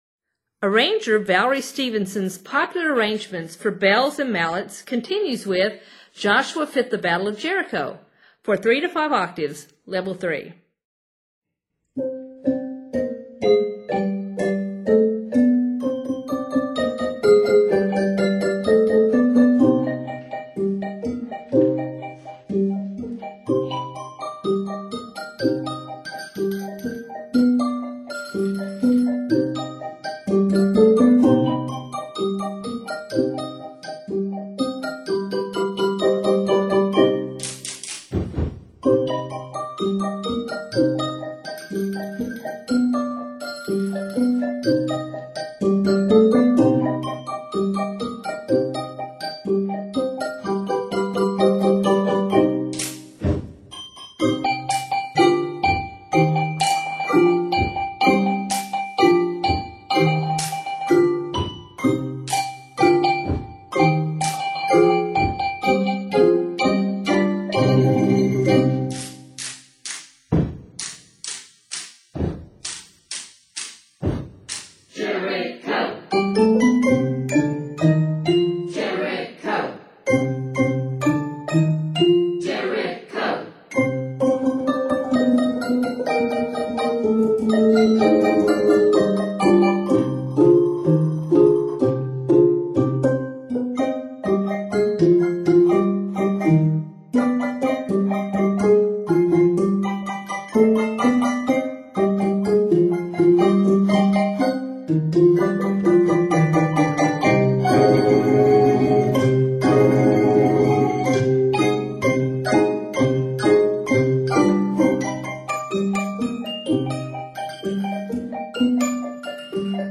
is all-malleted
The tempo is a lively quarter = 116-124.
It is scored in c minor.